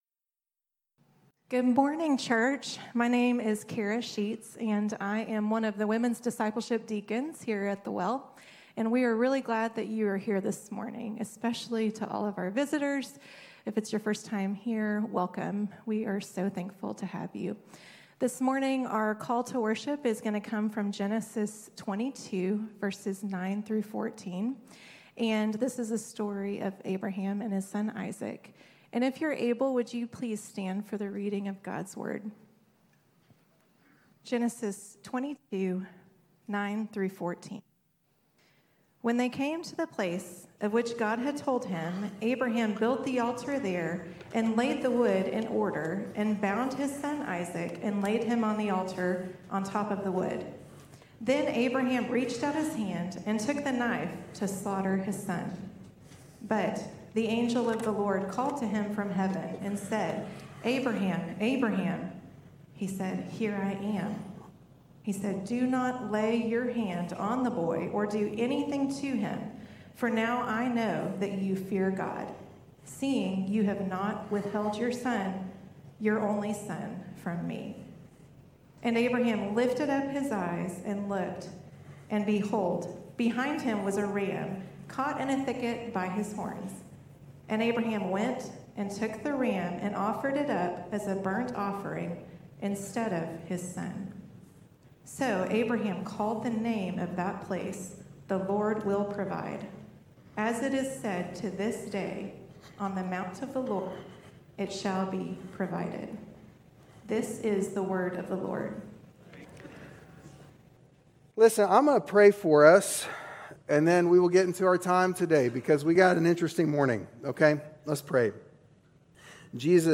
The Well Abilene Sermons